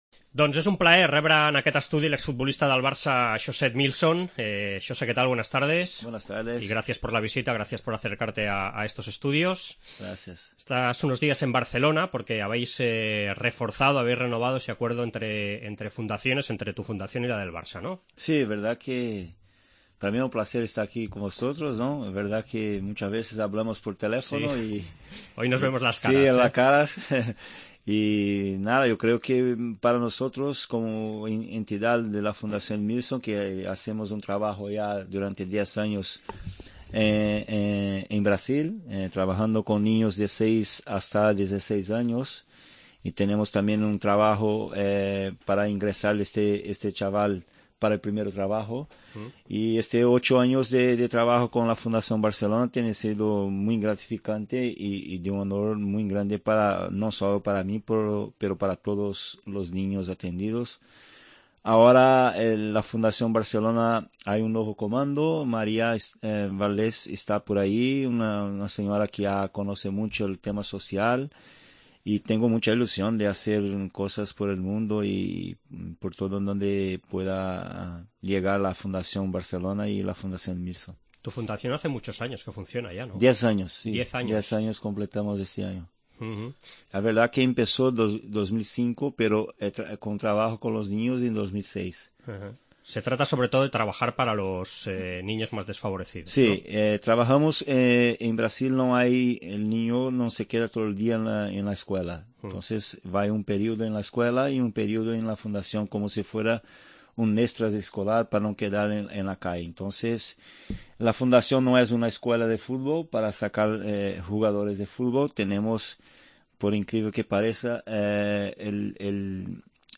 AUDIO: Entrevista a José Edmílson